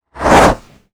MAGIC_SPELL_Sweep_mono.wav